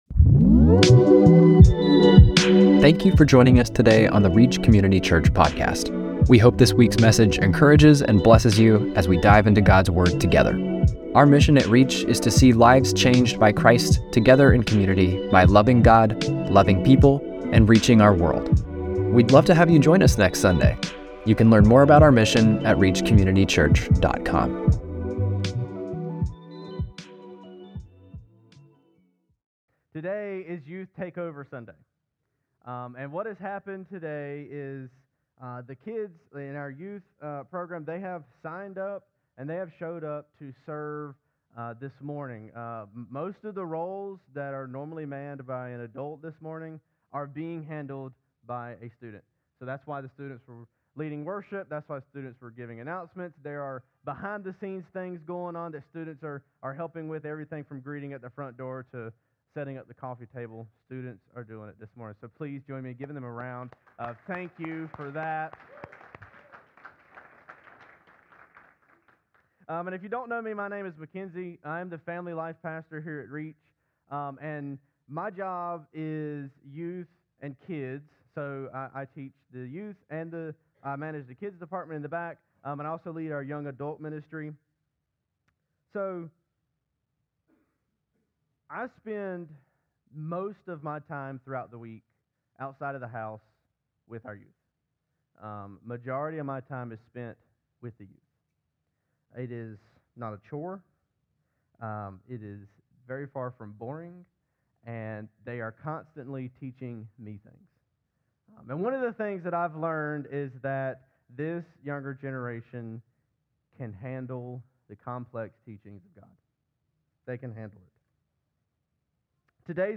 3-8-26-Sermon.mp3